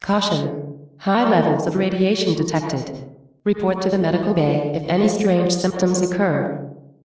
Shorten new voice announcements
radiation.ogg